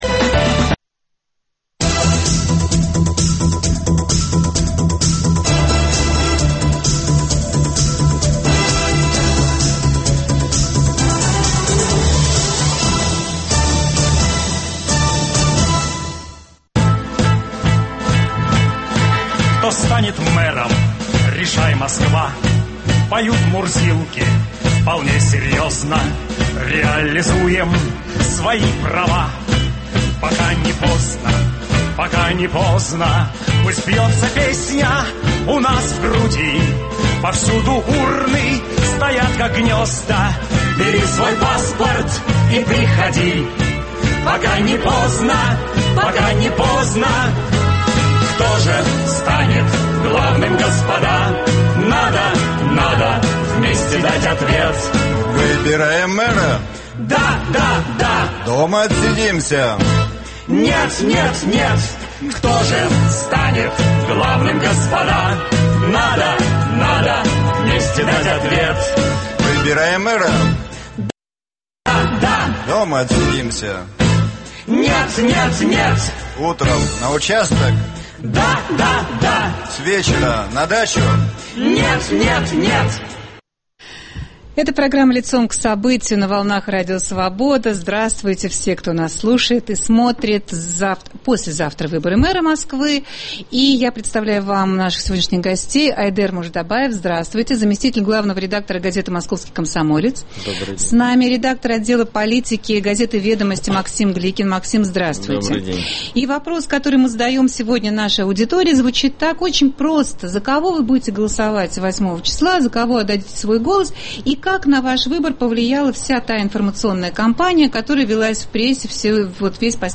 Вас ждут прямые видеовключения с митинга-концерта в поддержку Алексея Навального и от спорткомпекса "Олимпийский", куда на концерт в поддержку Сергея Собянина будут пытаться проникнуть наши корреспонденты.